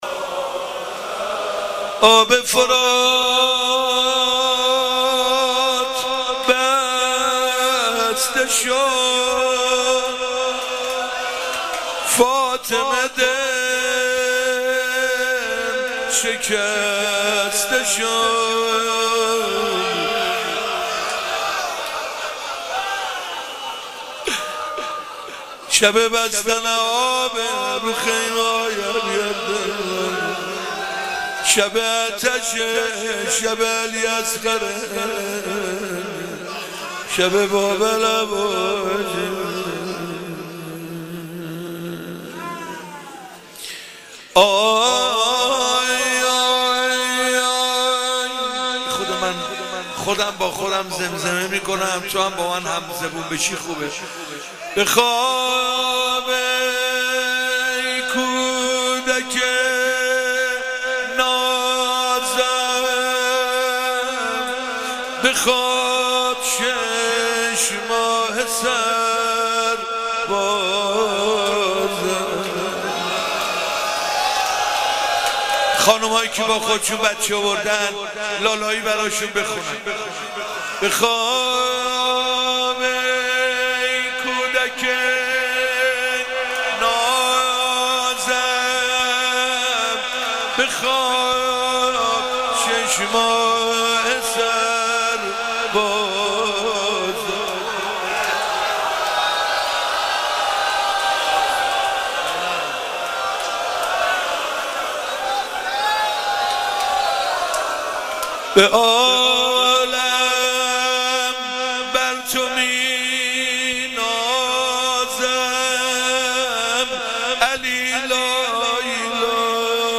شب هفتم محرم 95_غزل و روضه حضرت علی اصغر (ع)